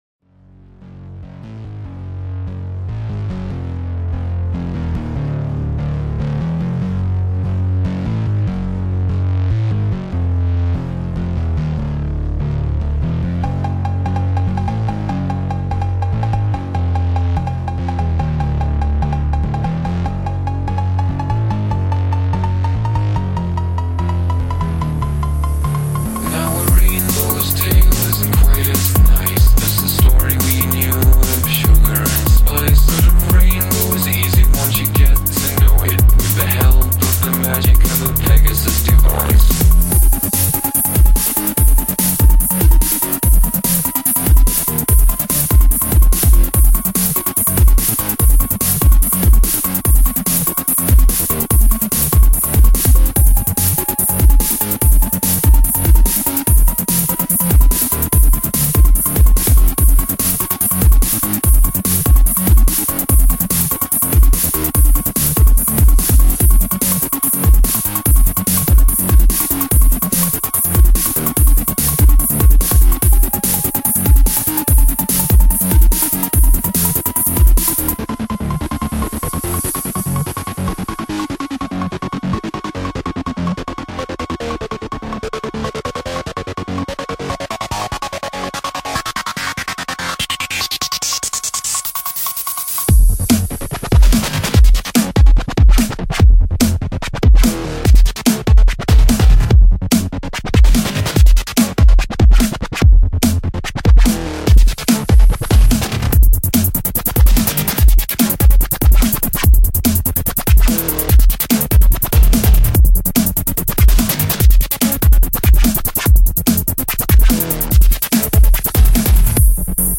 I didn't find any breakbeat remixes of this, so here you go!